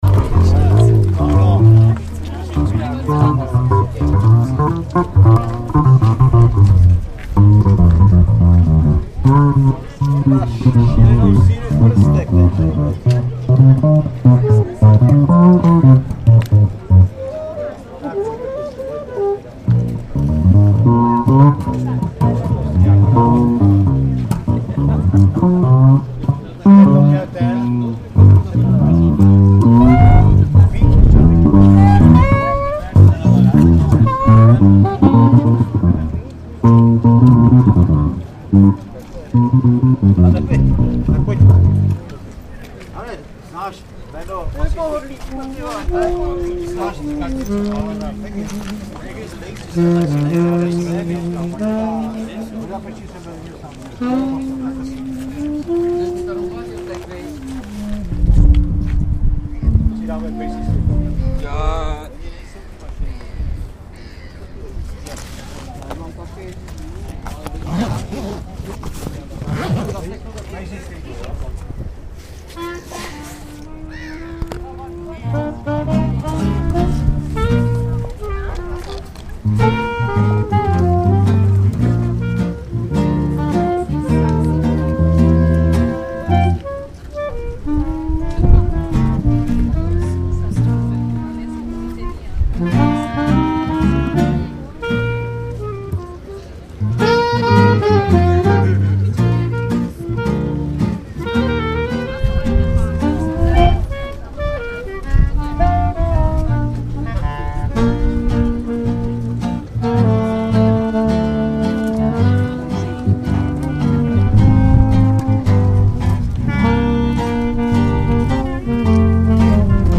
Swing band on Karlov Most (Charles' Bridge)